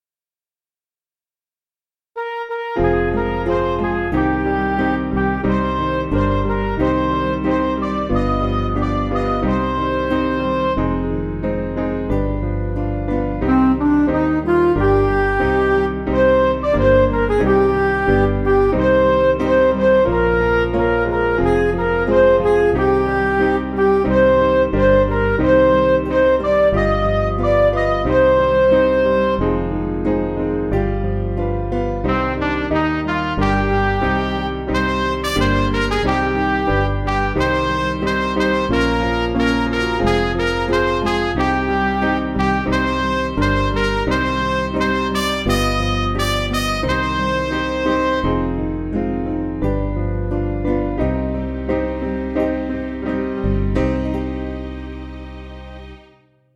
Piano & Instrumental
(CM)   2/Cm